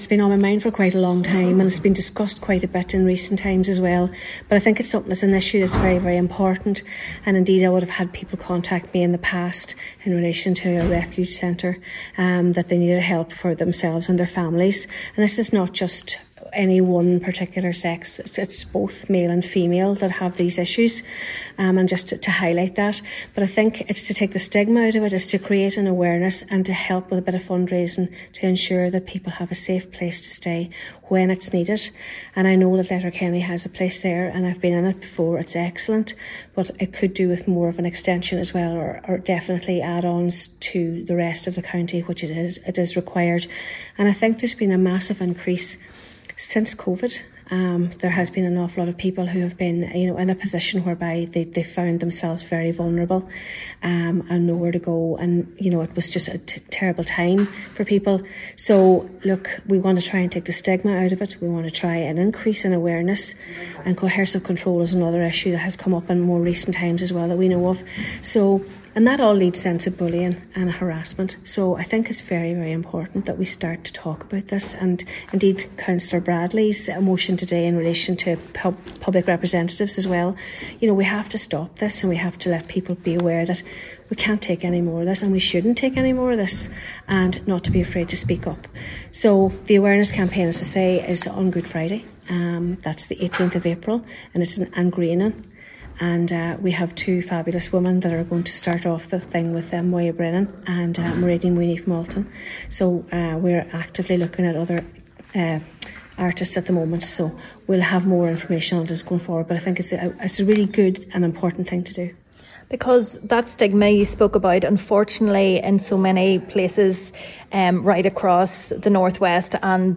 Cathaoirleach of Donegal County Council Councillor Niamh Kennedy says its vital proper resources are in place to protect those who are vulnerable: